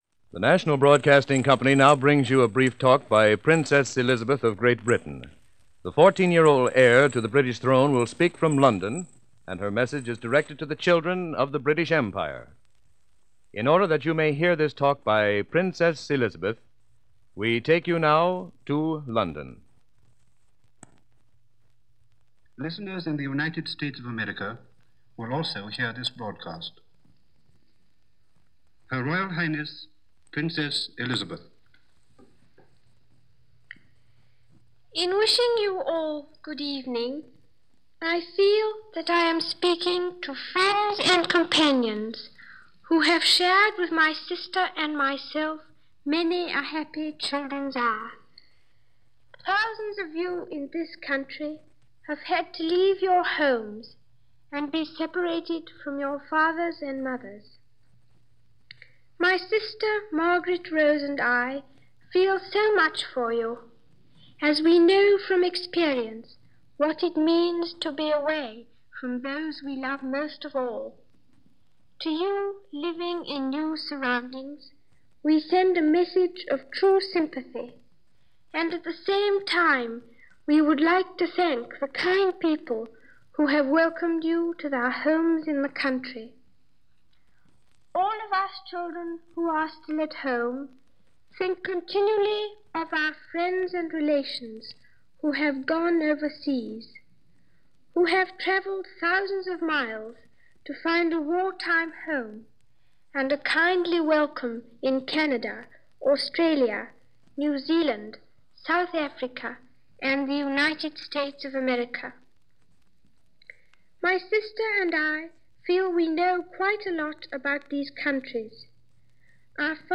Princesses Elizabeth and Margaret broadcasting to the world.
On October 13th 1940, the 14-year old Princess Elizabeth and her sister Princess Margaret, went before the microphone to deliver a pep talk to the children now away from their homes and scattered, in some cases, all over the world.
Here is that complete radio address from October 13, 1940.